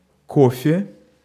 Ääntäminen
IPA: /ka.fe/